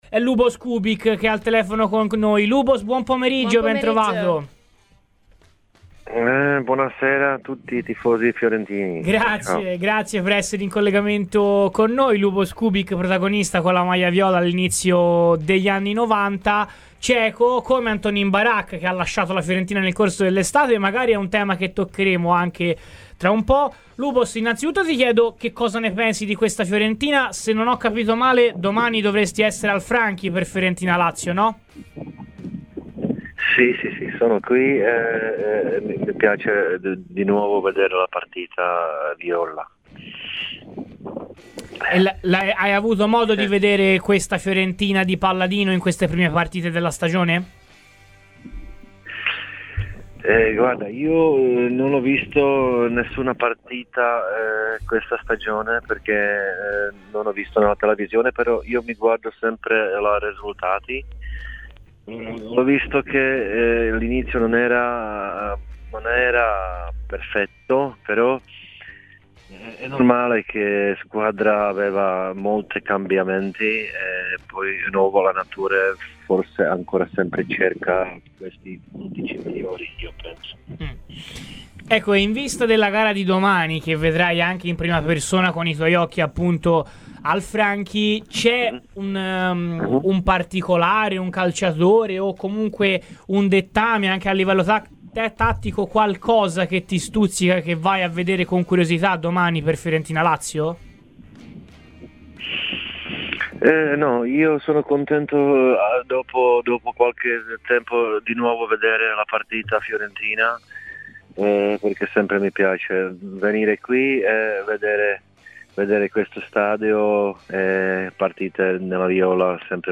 L'ex centrocampista ceco della Fiorentina, Luboš Kubík, è intervenuto a Radio FirenzeViola durante "Viola Week end" per parlare dell'attualità viola: "Domani sarò al Franchi, mi piacerà vedere la partita. Non ne ho ancora vista una in tv ma seguo sempre i risultati ed ho visto che l'inizio non è andato bene. Ma ovviamente la Fiorentina ha cambiato tanto e l'allenatore sarà ancora alla ricerca del miglior undici"